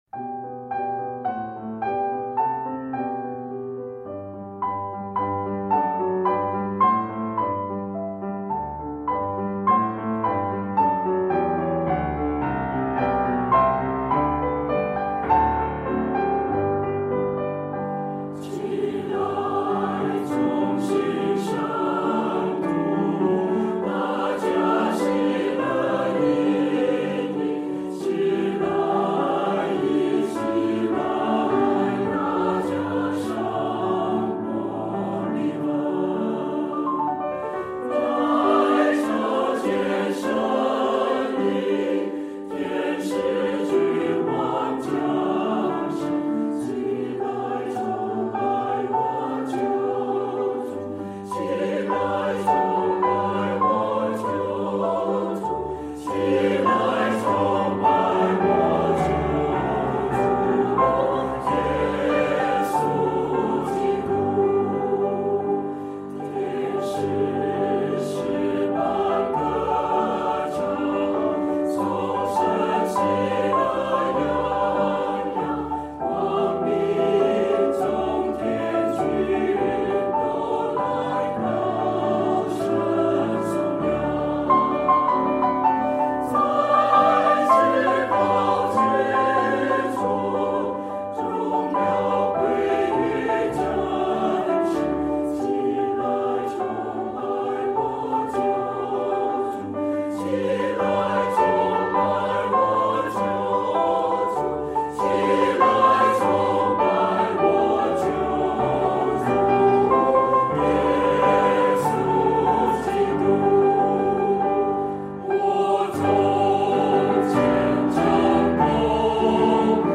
圣诞：祂为我们而生（含圣诗音频）
（芝加哥基督徒合唱团: 齐来崇拜）